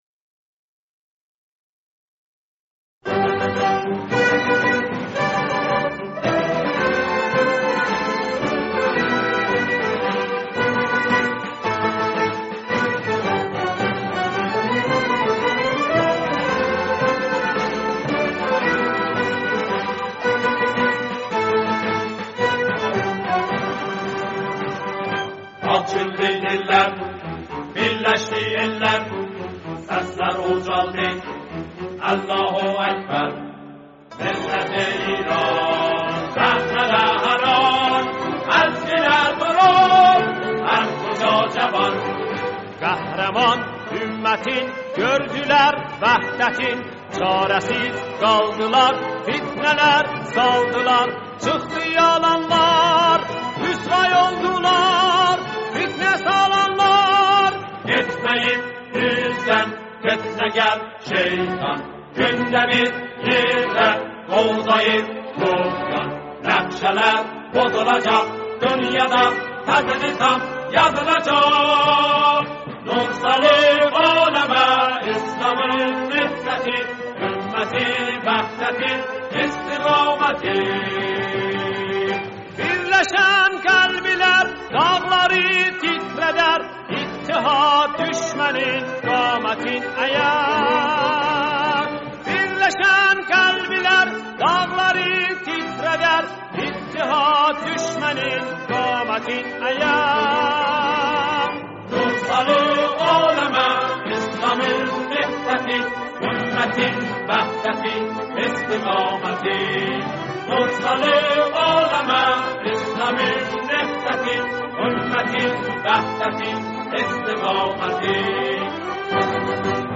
ترانه آذری با مضمون دفاع مقدس